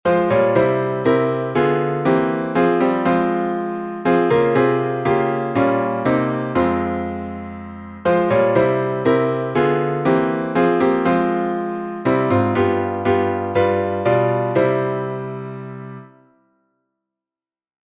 Key: f minor